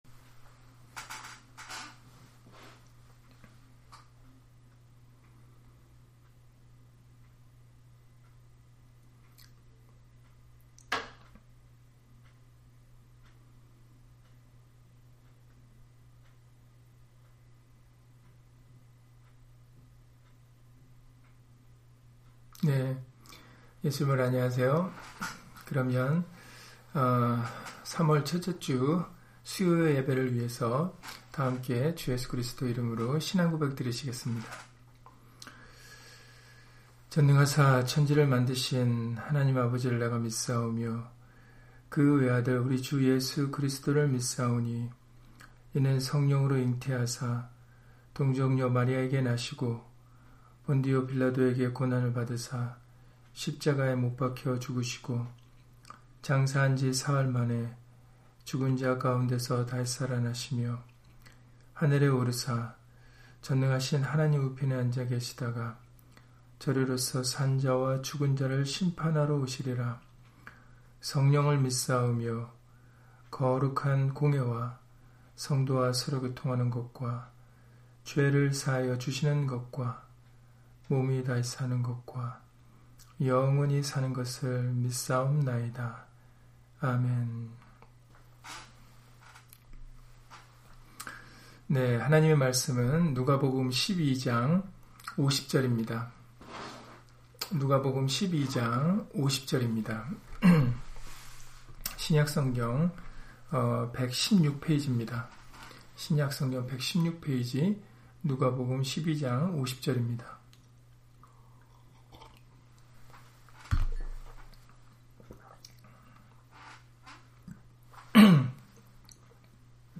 누가복음 12장 50절 [나는 받을 세례가 있으니] - 주일/수요예배 설교 - 주 예수 그리스도 이름 예배당